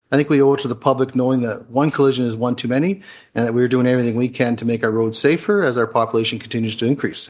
That’s due to a call for action to enhance signage and reduce accidents during Huron County Council’s meeting last Wednesday (March 6).
march-11-hc-council-meeting-stop-signs-finch-2.mp3